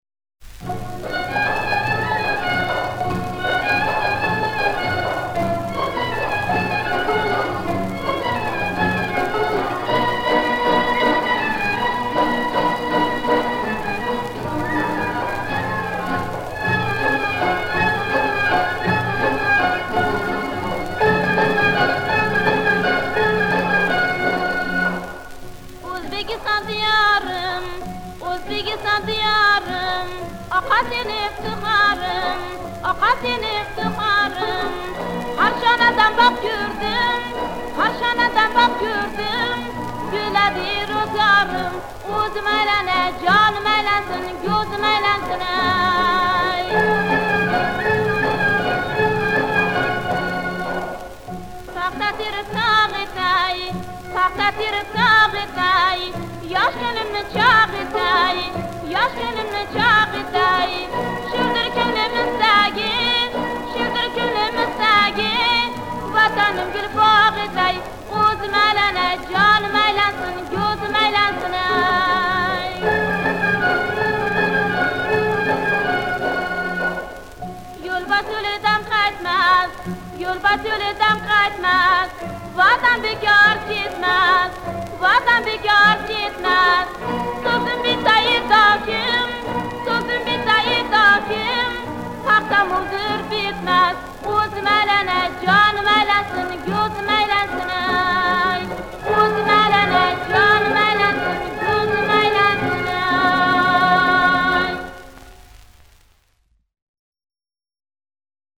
Genre: Early Ethnic